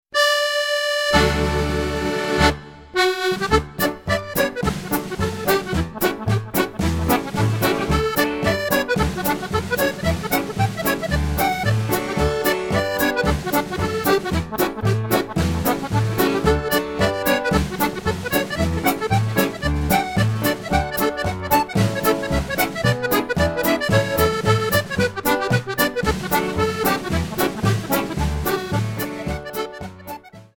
accordion
drums